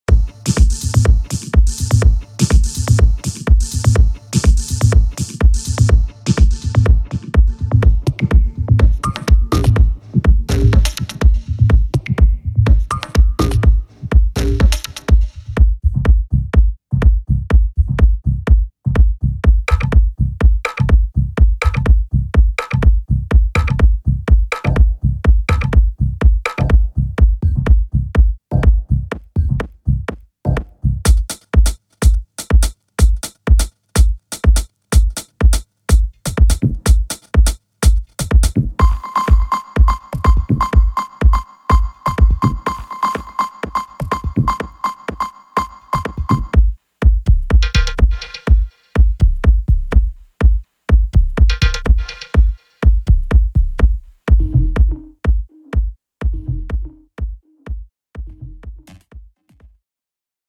Melodic Techno
所有循环均以124 BPM的速度录制。
All loops have been recorded at 124 BPM.